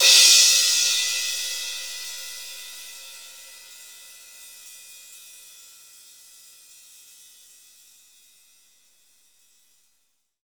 Index of /90_sSampleCDs/Roland L-CDX-01/CYM_Cymbals 1/CYM_Cym Modules
CYM ROCK 0EL.wav